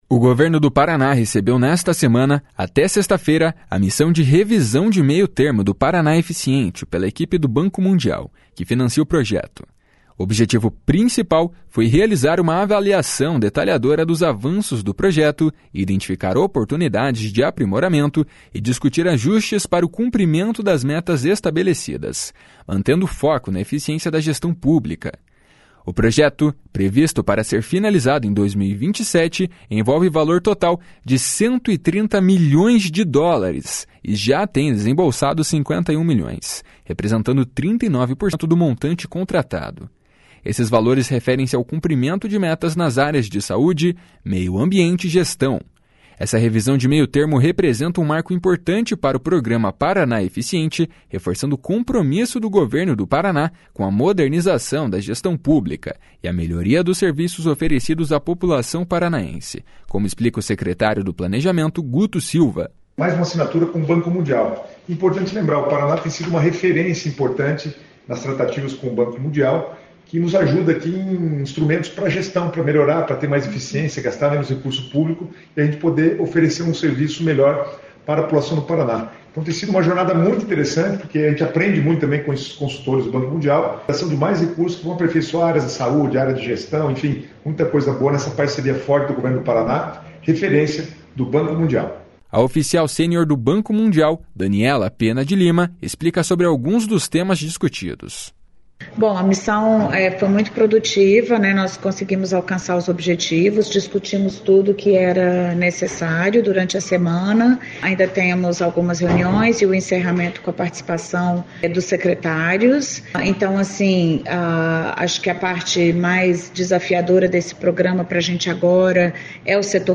Essa revisão de meio termo representa um marco importante para o Programa Paraná Eficiente, reforçando o compromisso do Governo do Paraná com a modernização da gestão pública e a melhoria dos serviços oferecidos à população paranaense, como explica o secretário do Planejamento, Guto Silva.